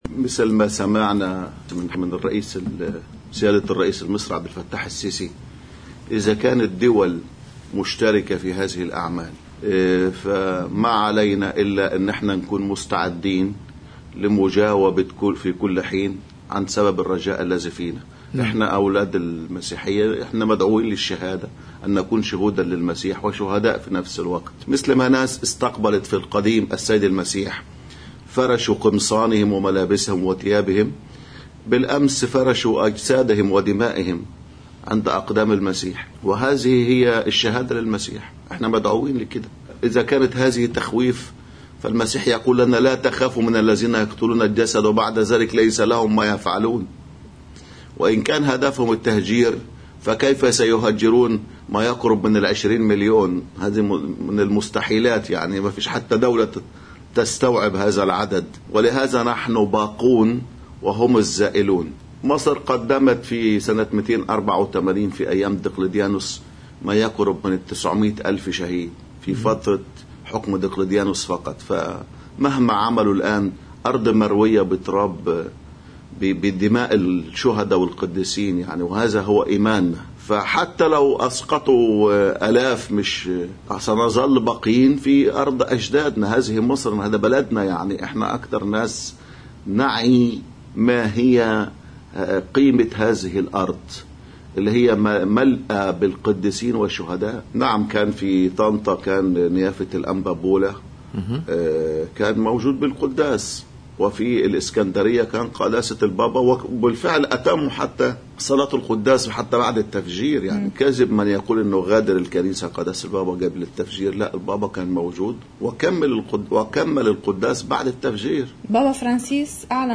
مقتطف من حديث